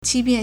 欺骗 (欺騙) qīpiàn
qi1pian4.mp3